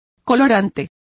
Complete with pronunciation of the translation of colourings.